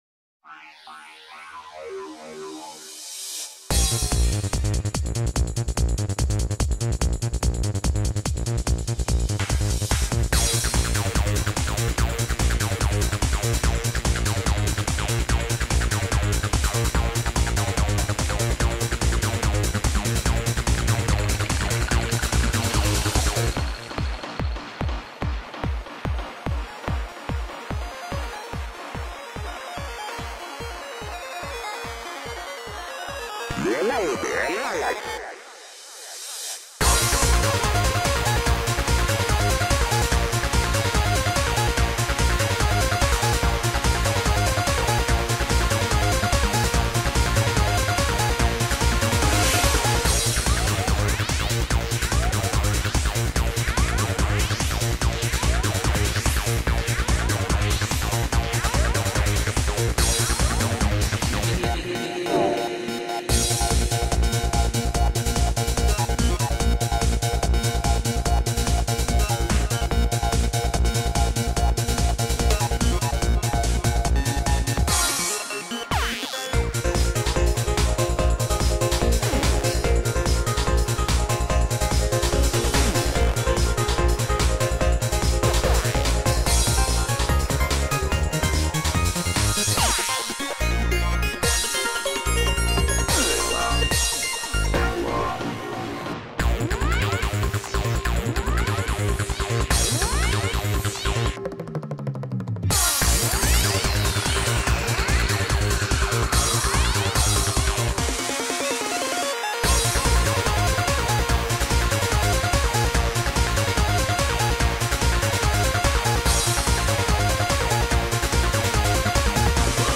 BPM145
Audio QualityLine Out